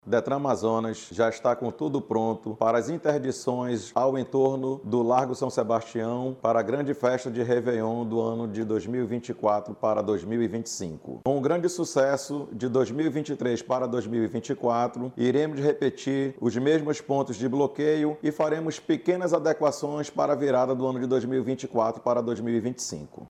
O diretor-presidente do Detran Amazonas, Wendell Waughan, explica que serão implantados os mesmos pontos de bloqueios feitos no réveillon passado.